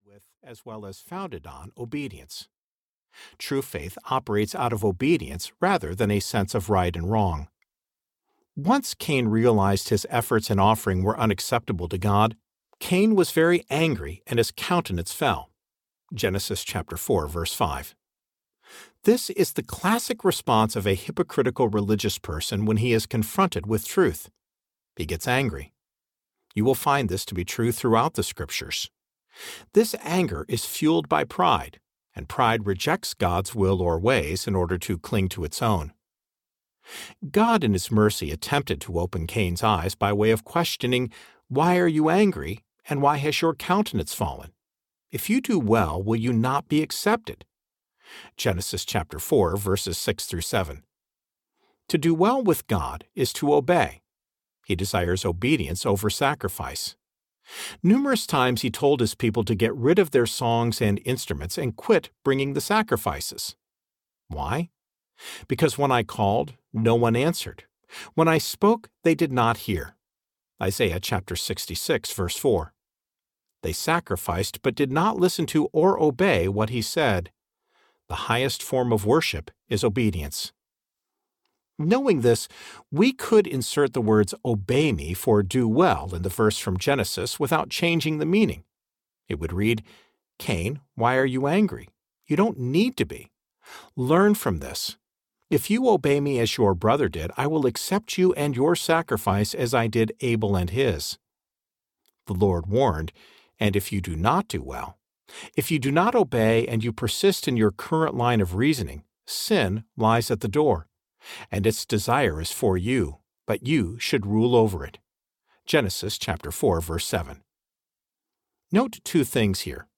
Under Cover Audiobook
Narrator